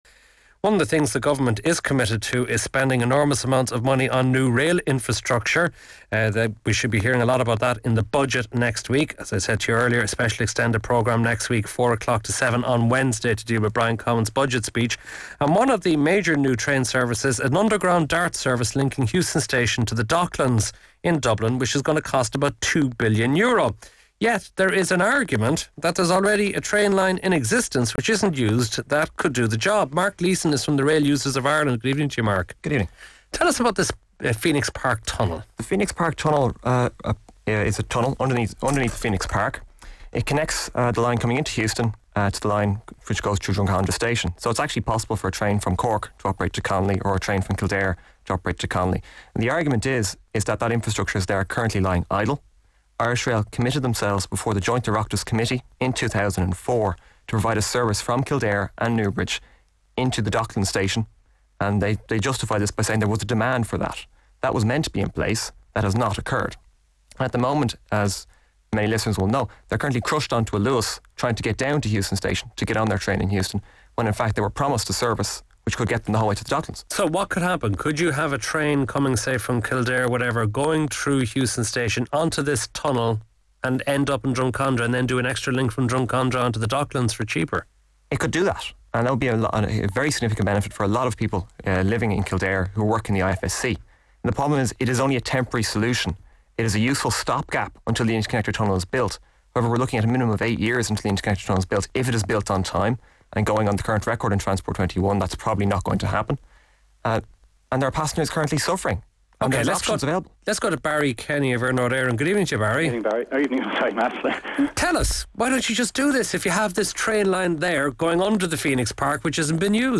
Discussion about unused Phoenix Park Tunnel in Dublin which could provide quick relief to some of the capitals transport problems.
Matt Cooper is the presenter.